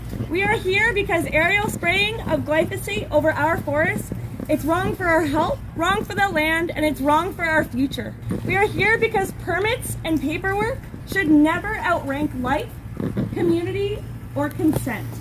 The Ministry of Natural Resources office on Riverside Drive in Pembroke was the site of a rally on Tuesday afternoon to stop the spray of herbicides, namely glyphosate, on Renfrew County forests.